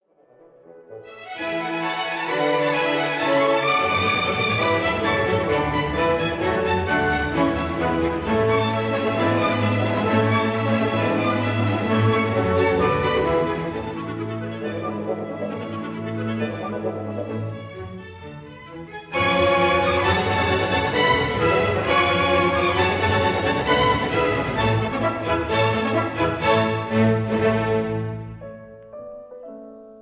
This is a 1960 stereo recording